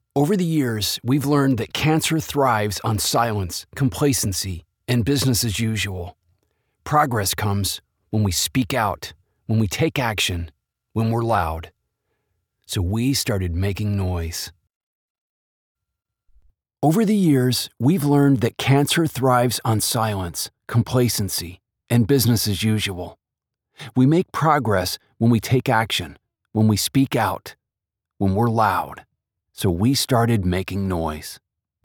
Both mics have been processed and positioned individually to play on their strengths....can you tell which one is the U87ai?
U87ai vs. ???
It was a tad boomy.
dude, they both sound over processed. something is off.. they sound like someone was heavy handed on the top end enhancement on a DBX 286...
Mic_A_vs._Mic_B.mp3